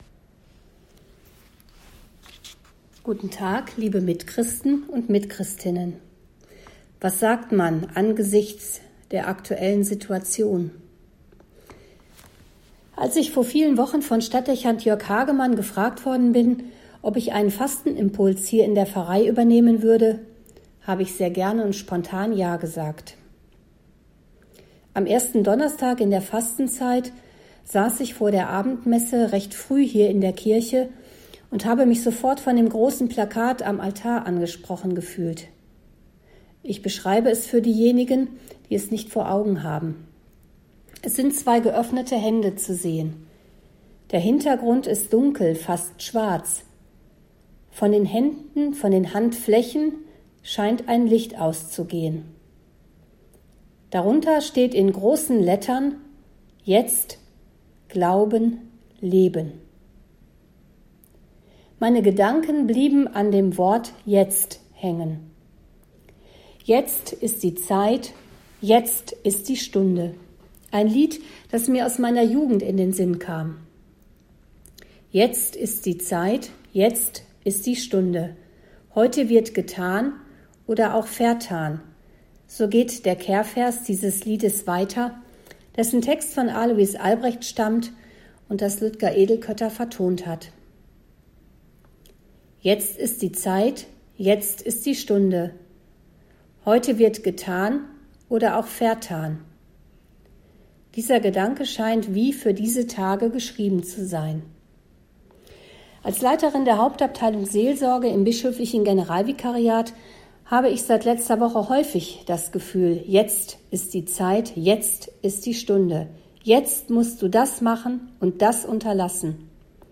Fastenpredigt zum 19.03.2020